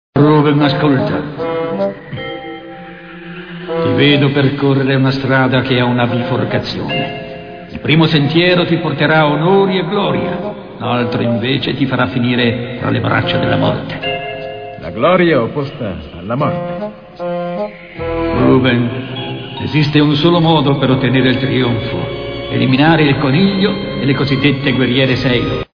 nel cartone animato "Sailor Moon, la luna splende", in cui doppia il Vecchio Saggio.